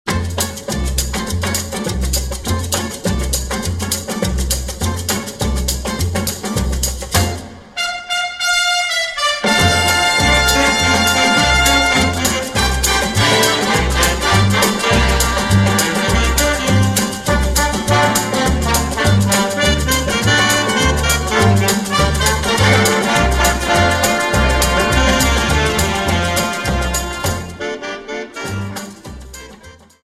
Samba 50 Song